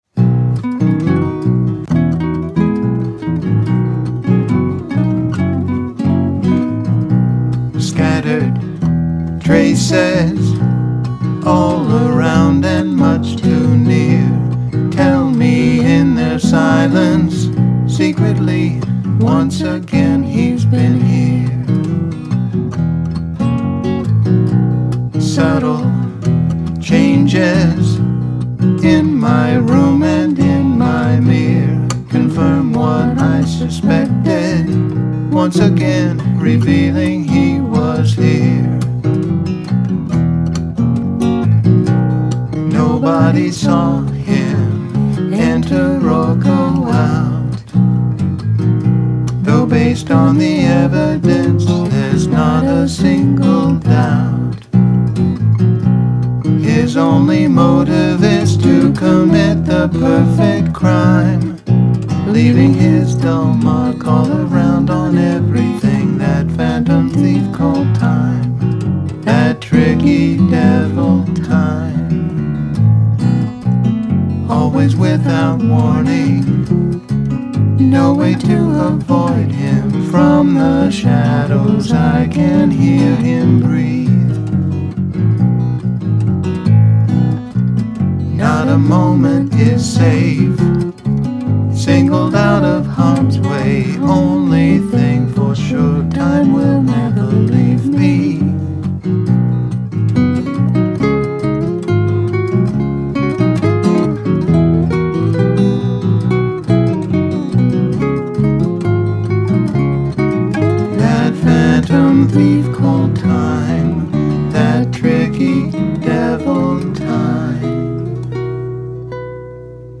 guitar and vocals